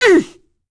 Demia-Vox_Damage_02.wav